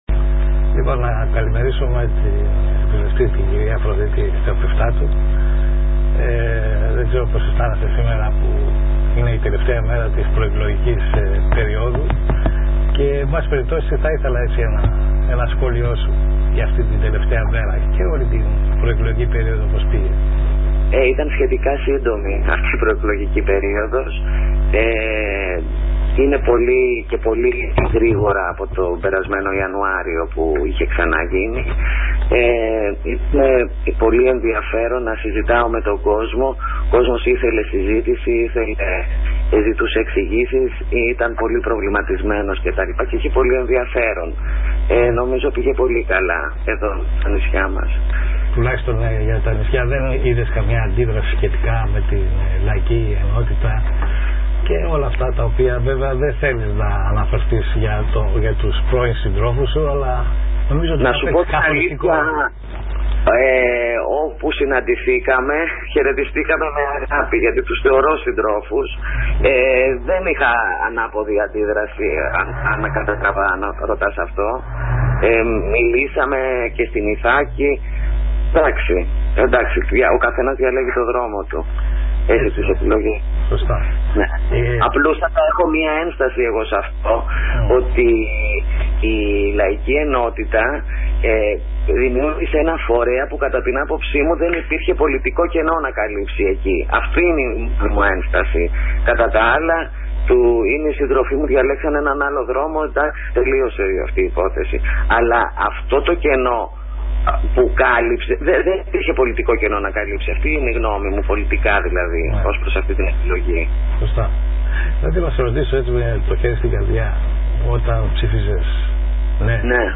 Μια δεκάλεπτη συνομιλία – συνέντευξη είχα με την υπ. βουλευτή κ. Αφροδίτη Θεοπεφτάτου. Εντύπωση μου προκάλεσε το γεγονός ότι η κ. Θεοπεφτάτου με ειλικρίνεια μου δήλωσε ότι η πρώτη της σκέψη ήταν να μην ψηφίσει το μνημόνιο, να παραδώσει την έδρα,. και να φύγει, αλλά μετά σκέφτηκε ότι αυτή η “πρώτη αριστερά” κυβέρνηση θέλει στήριγμα.Εντύπωση επίσης μου προκάλεσε που παραδέχθηκε ότι με τον Δήμο δεν είχε καμία συνεργασία.